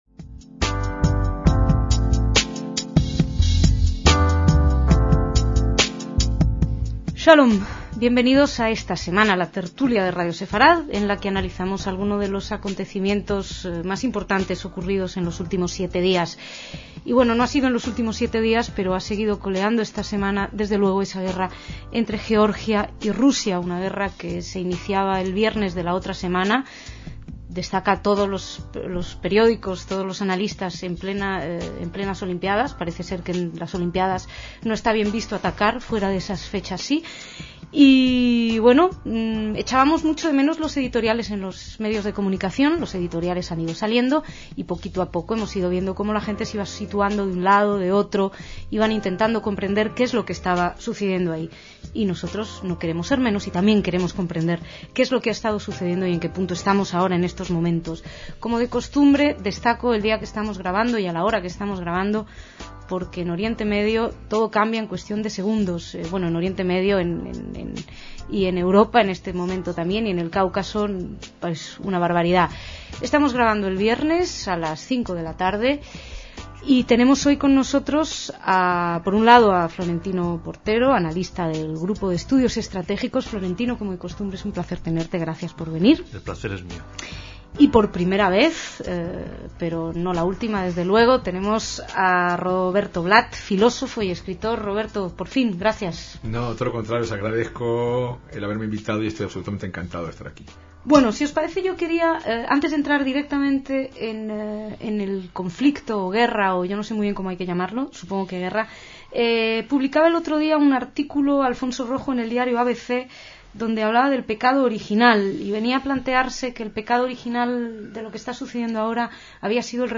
DECÍAMOS AYER (16/8/2008) - Kosovo, Rusia, Georgia, la Unión Europea y, como no podía ser menos, Israel, fueron algunos de los protagonistas del debate de aquella semana del verano de 2008.